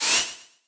sam_reload_01.ogg